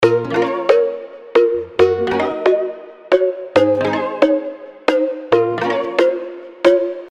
• Качество: 320, Stereo
без слов
короткие
мексиканские
ксилофон